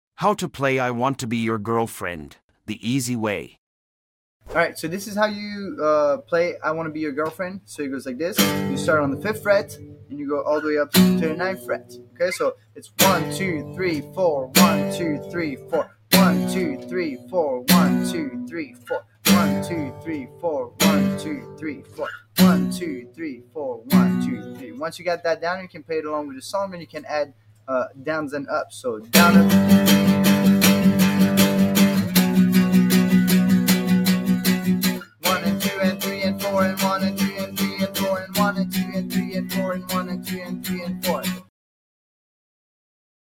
Easy Guitar Tutorial